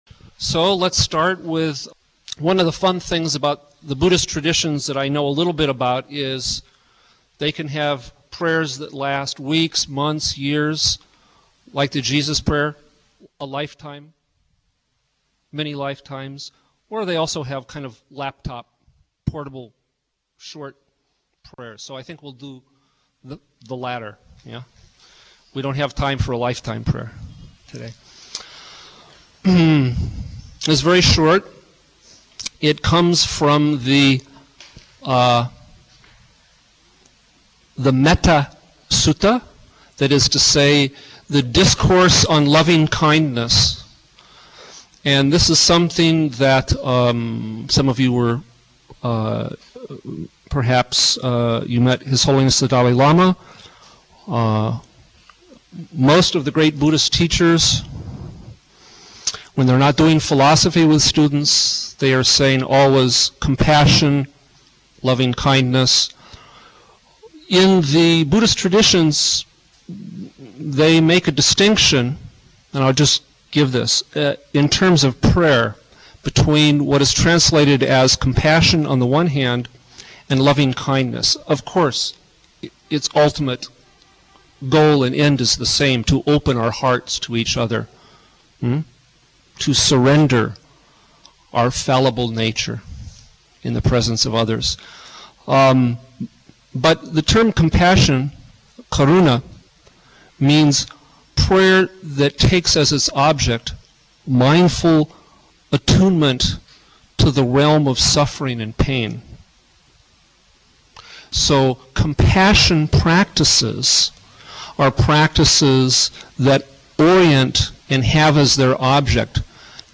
Audio Lectures on topics like interfaith alliance,interfaith christian,interfaith community,interfaith council,interfaith jewish